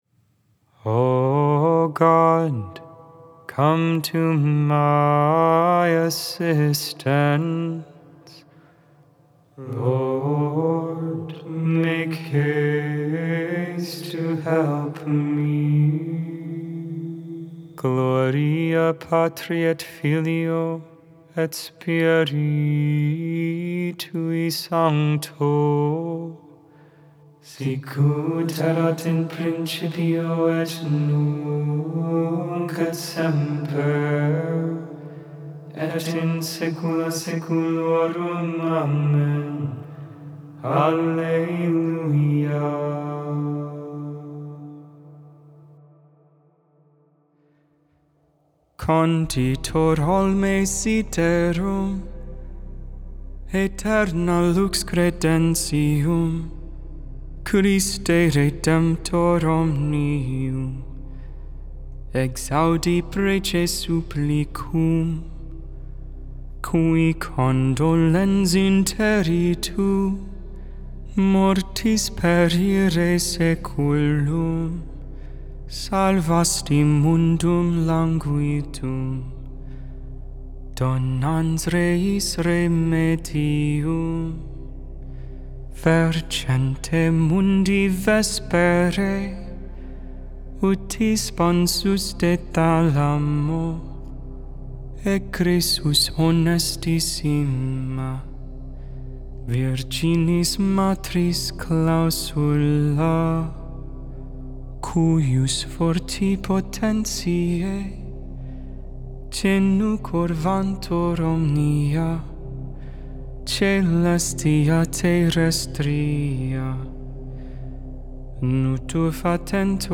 Vespers, Evening Prayer for the 3rd Sunday in Advent, December 15, 2024.